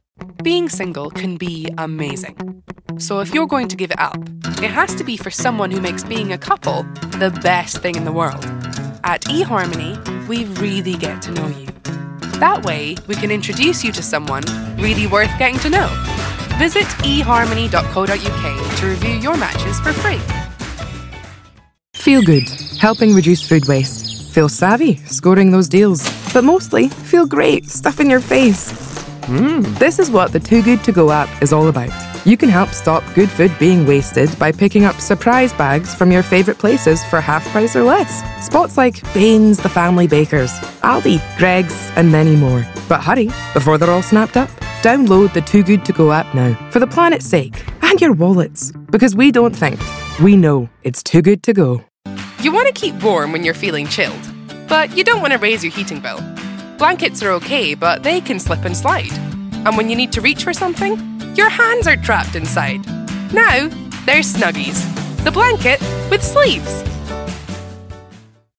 20s-40s. Female. Scottish.